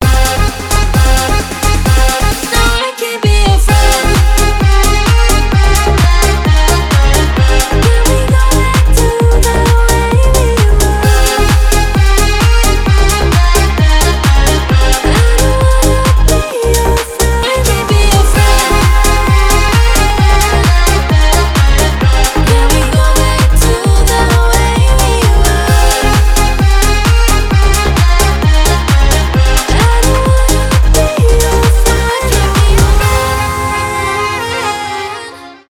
заводные
танцевальные